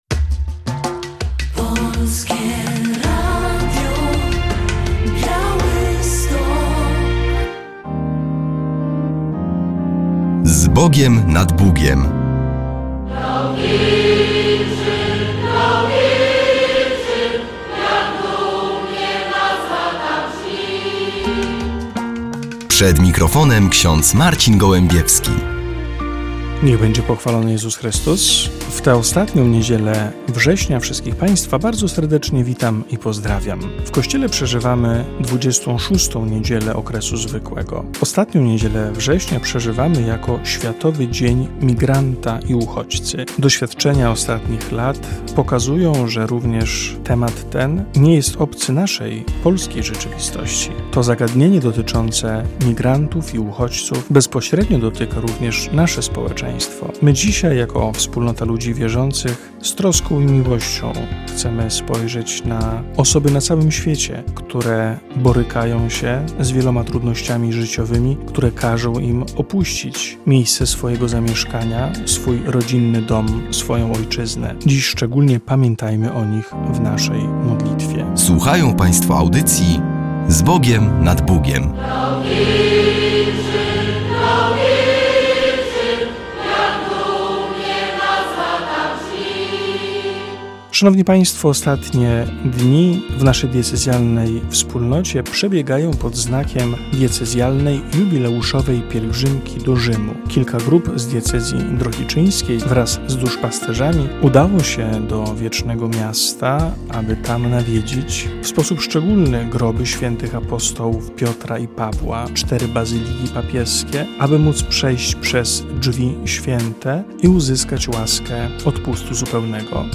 W audycji relacja z diecezjalnej pielgrzymki do Rzymu z okazji Roku Jubileuszowego 2025.